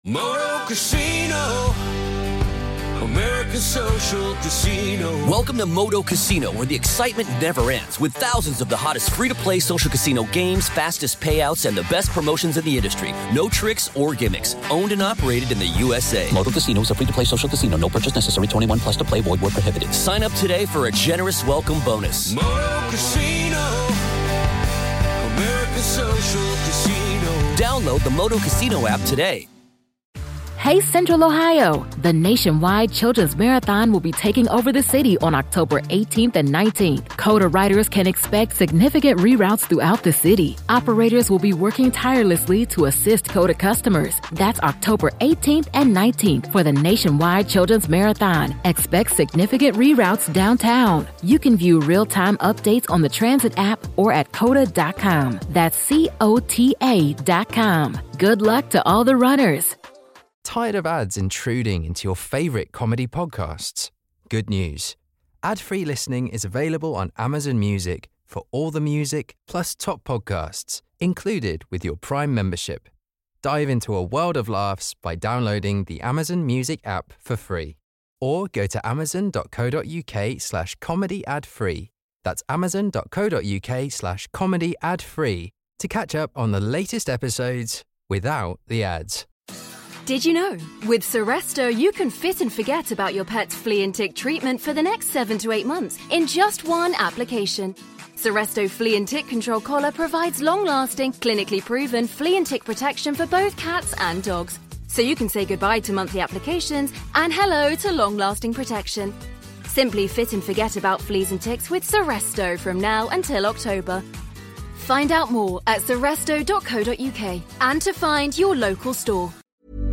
This week we are joined with singer Raye and we talk about Tracy Beaker’s return to the dumping ground, Love Island pregnancies and somehow we get onto Peppa Pig....